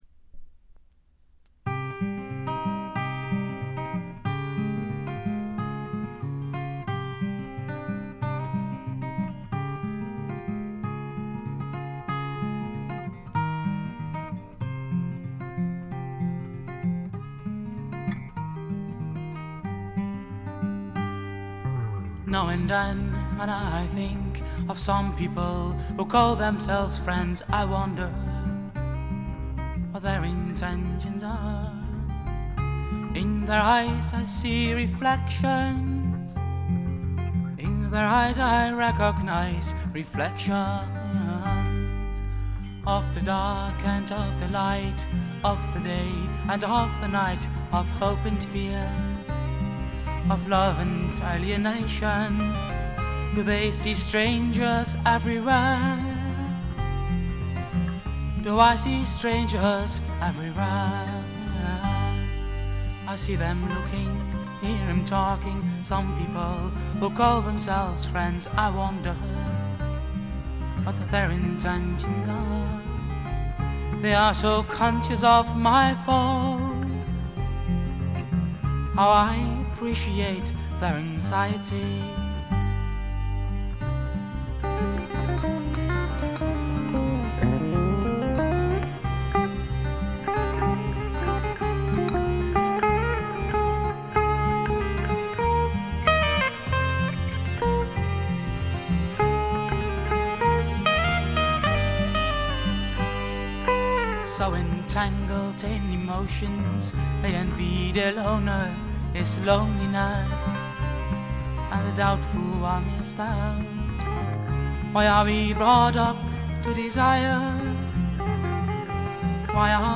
軽いチリプチが少々。
全体を貫く哀愁味、きらきらとしたアコースティックな美しさは文句なしに至上のもの。
試聴曲は現品からの取り込み音源です。